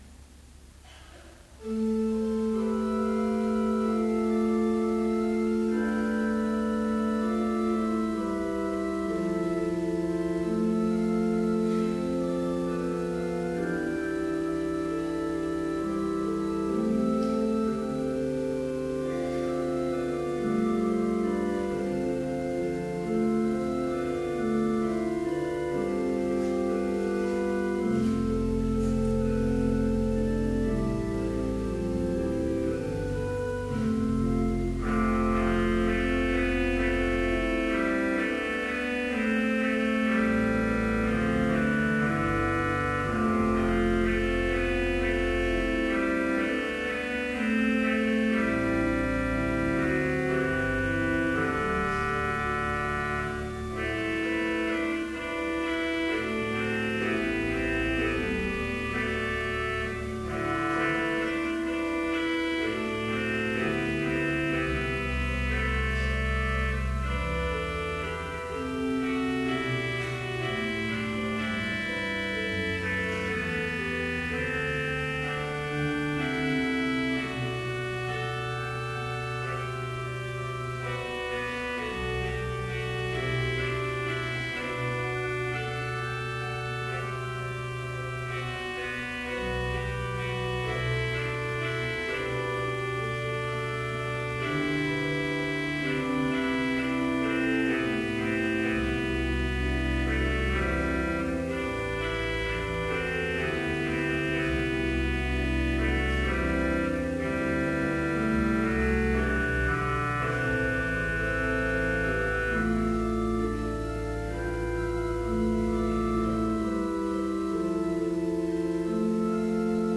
Vespers service in Bethany Lutheran College's chapel
Complete service audio for Evening Vespers - February 12, 2014
Versicles & Gloria Patri (led by the choir)